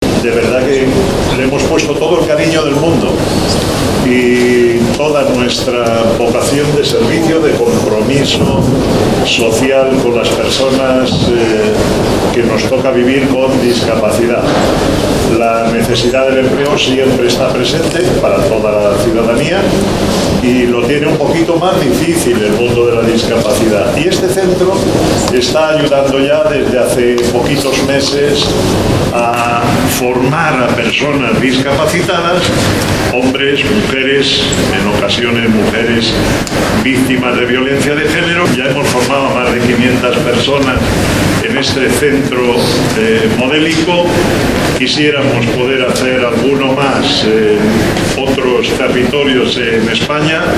durante el acto de inauguración de la sede.